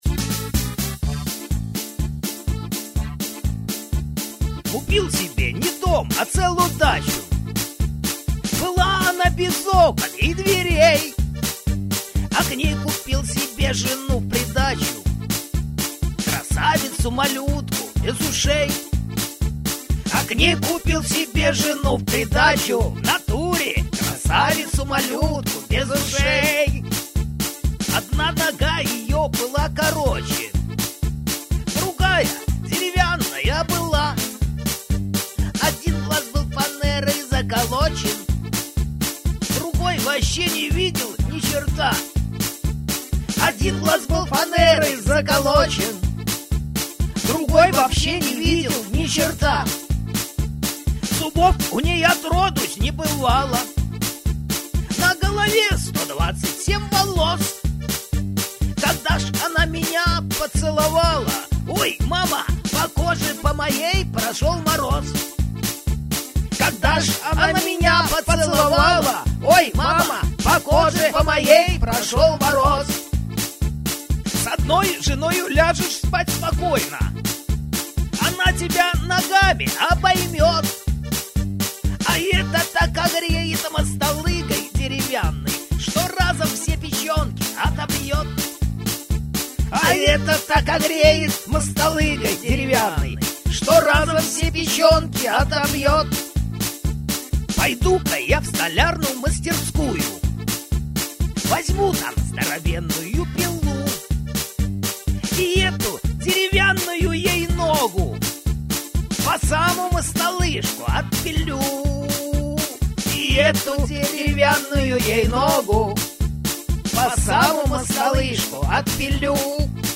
• Жанр: Комедия